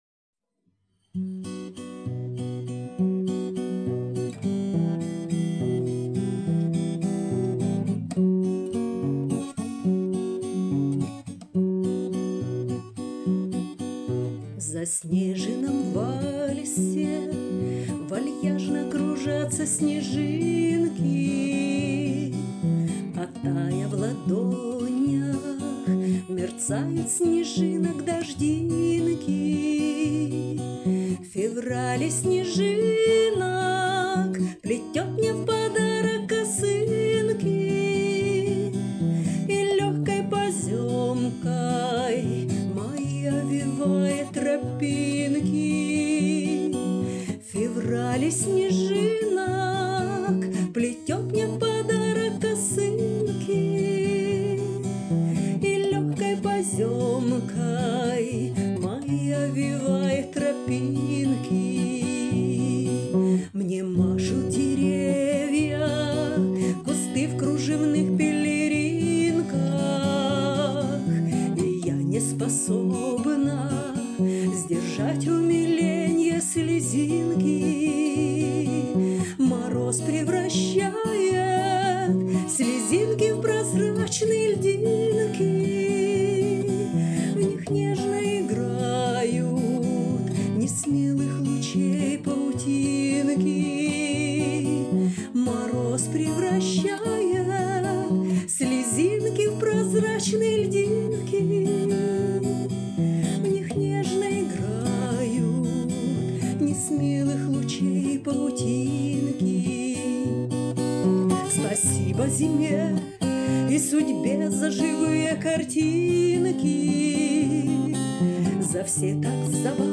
Номинация «ПЕСНЯ»